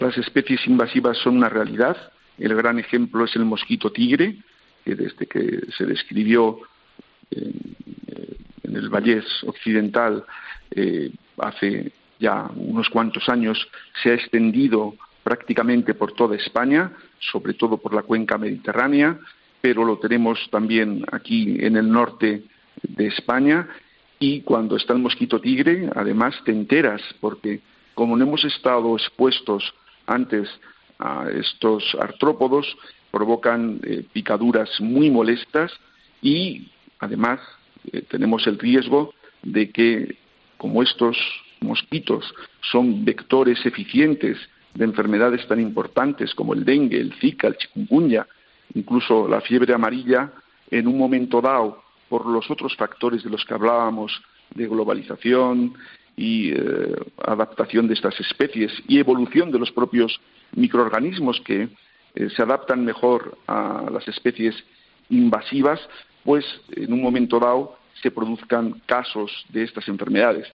Este es el insecto que se propaga con el calor. Lo cuenta un experto en Herrera en COPE